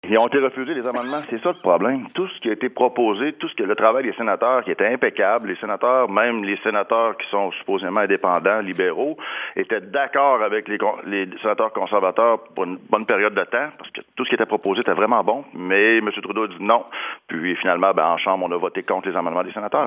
Por otra parte, el diputado conservador Pierre Paul-Hus, en entrevista con la radio francesa de Radio Canadá, decía este lunes por la mañana que hay que ser realista y aceptar que no es posible criminalizar nuevamente el consumo de marihuana en el país.